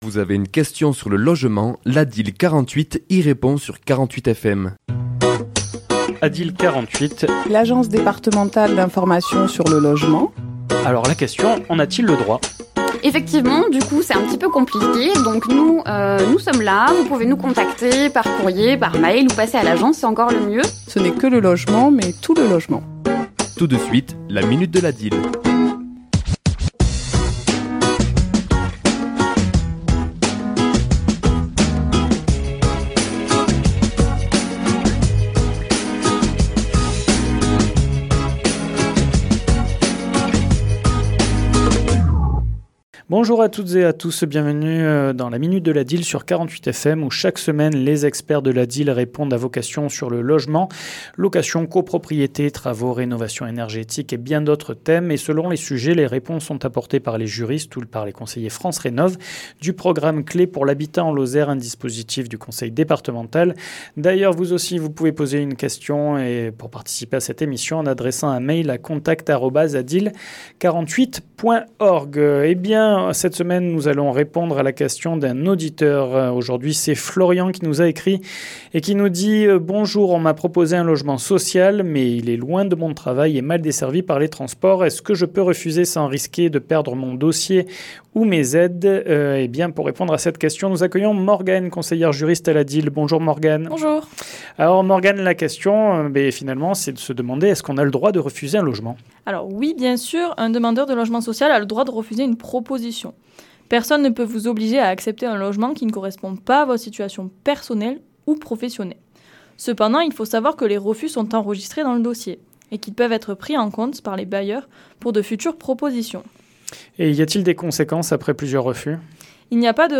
Chronique diffusée le mardi 17 février à 11h et 17h10